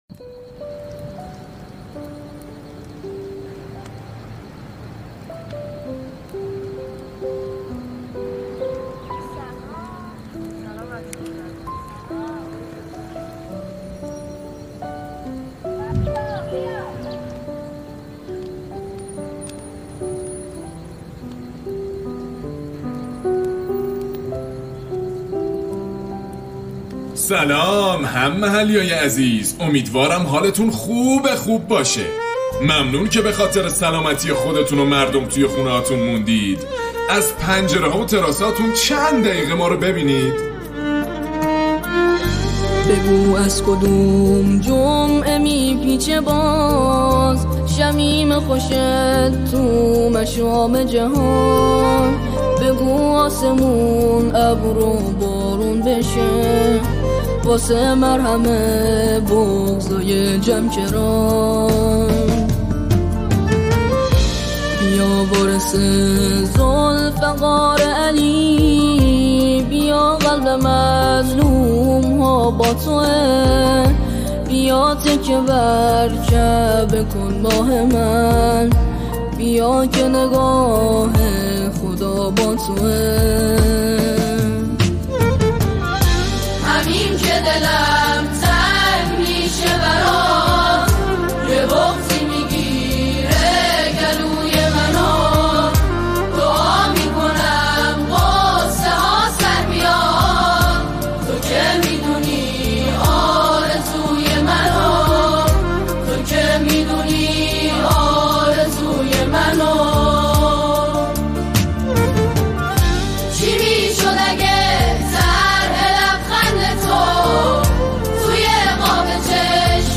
نماهنگ دلنشین و پر احساس